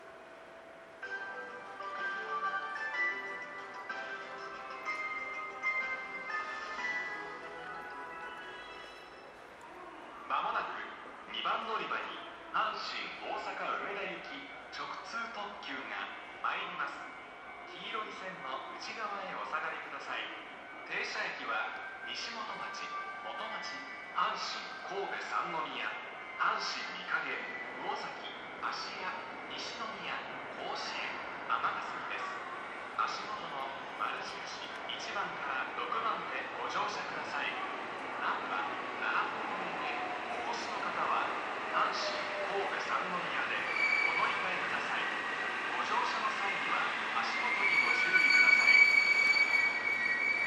この駅では接近放送が設置されています。
接近放送直通特急　大阪梅田行き接近放送です。